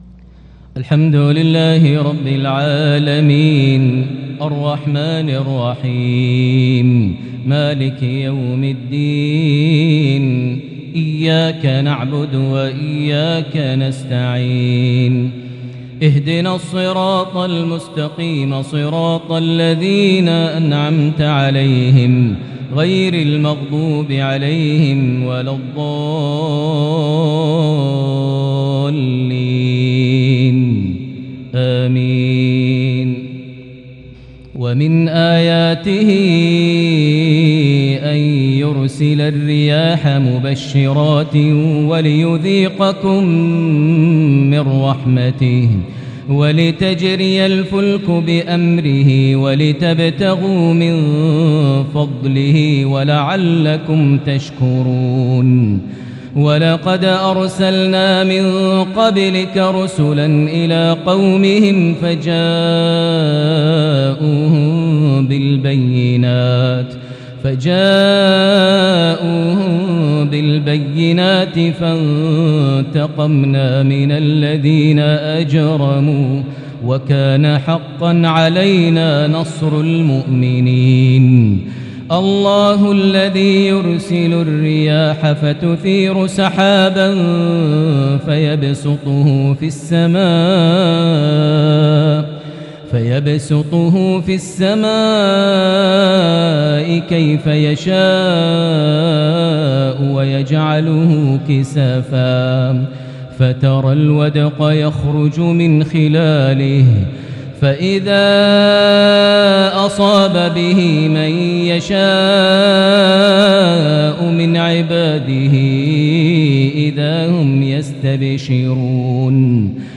lsha 1-1-2022 prayer from Surah Ar-Rum 48-60 > 1443 H > Prayers - Maher Almuaiqly Recitations